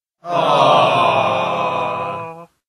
Oooooh de decepcion desilusion